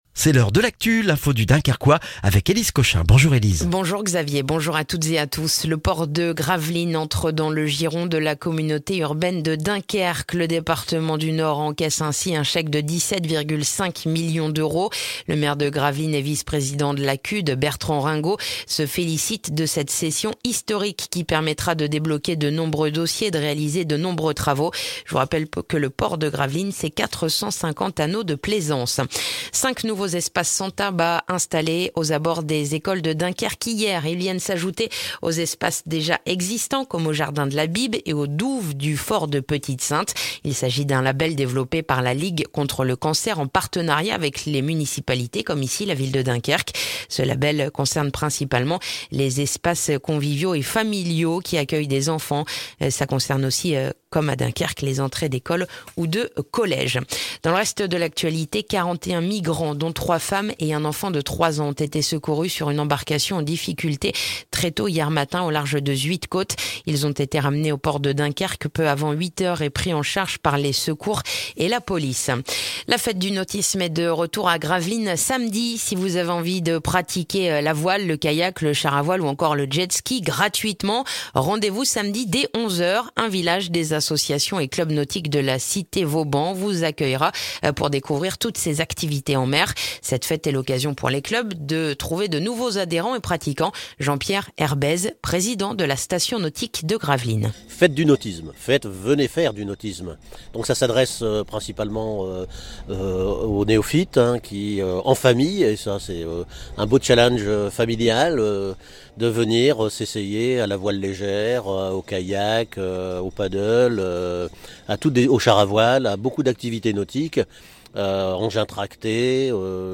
Le journal du mercredi 1er juin dans le dunkerquois